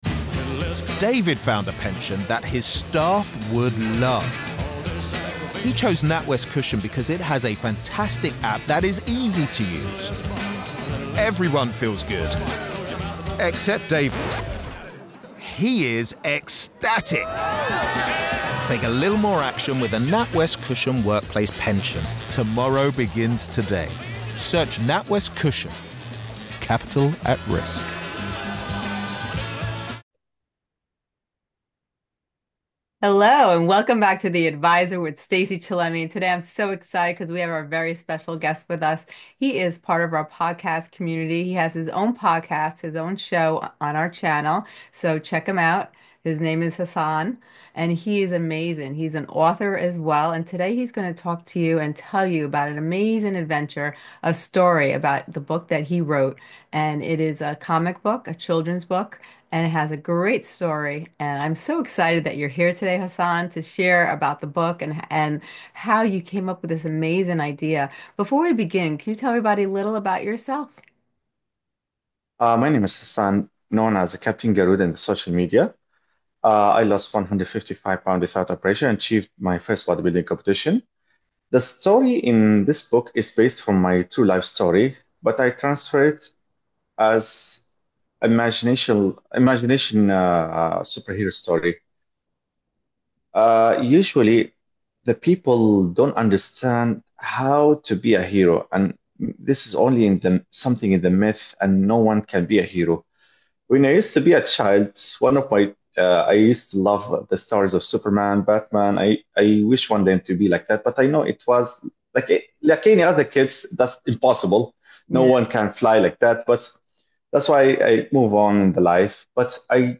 Get ready to be inspired, uplifted, and motivated to embrace your own heroic potential in this engaging conversation.